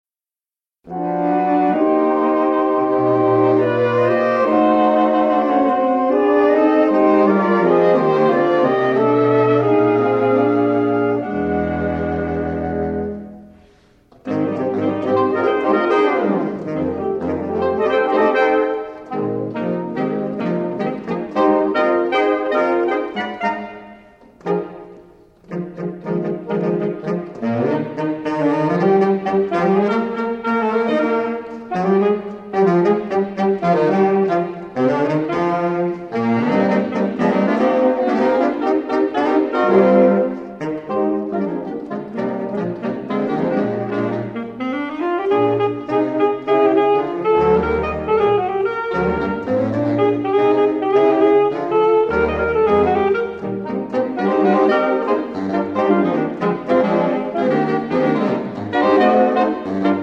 live recordings
baritone saxophone
tenor saxophone
alto saxophone
soprano and alto saxophone